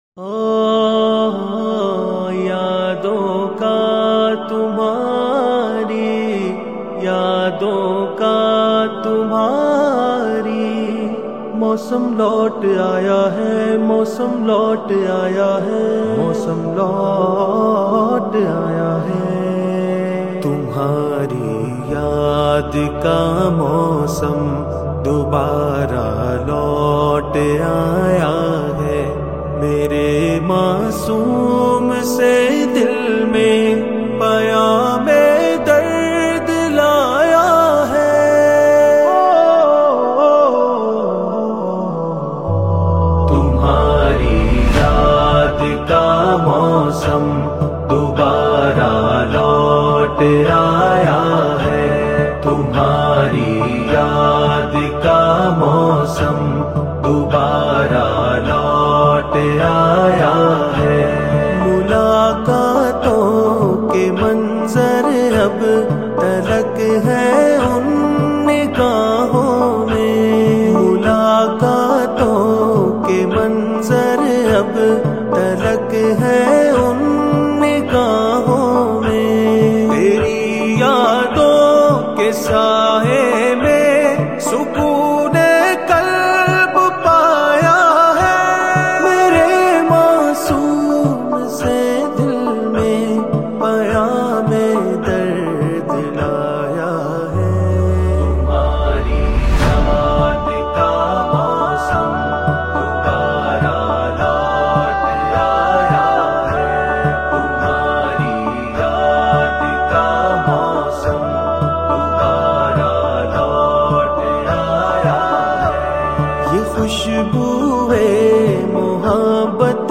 Most Beautiful Ghazl Without Music | Tumhari yadon Ka mausam
Naats